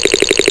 rolladen.wav